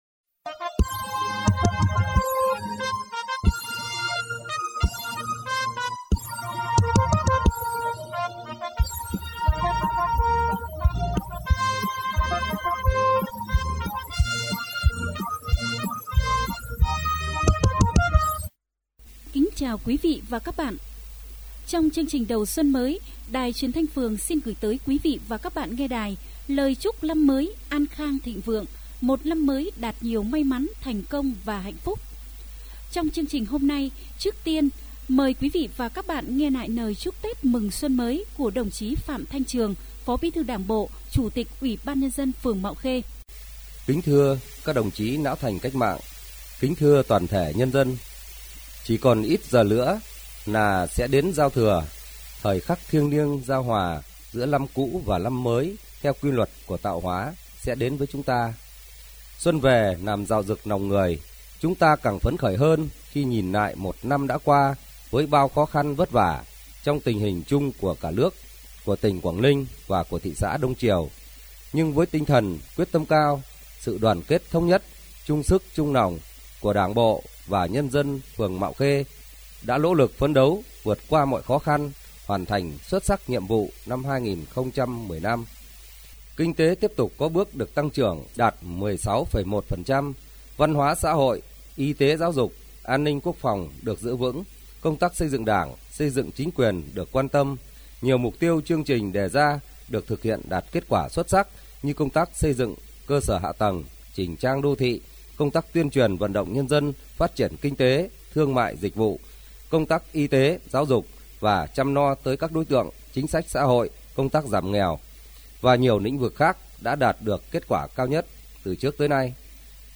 Chương trình được phát trên sóng FM truyền thanh phường Mạo Khê ngày đầu năm Bính Thân 2016